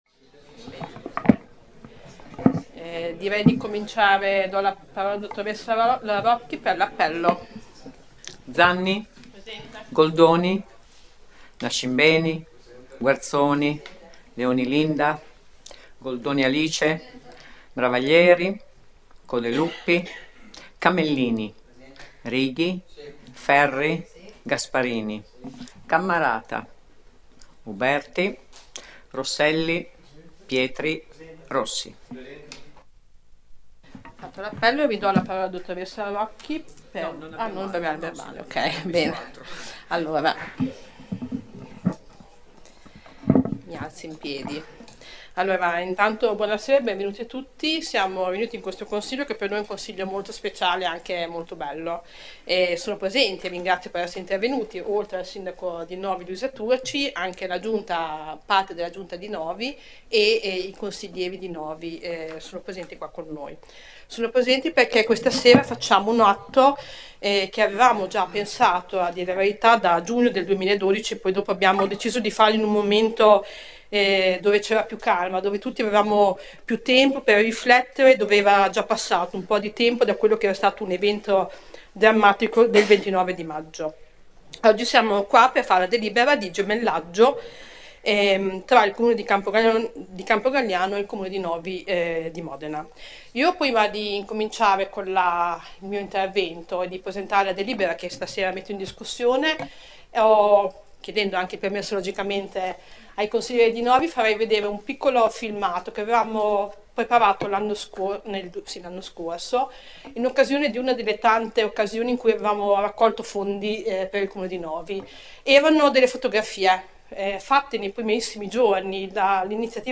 Accedendo a questa pagina è possibile ascoltare la registrazione della seduta del Consiglio comunale.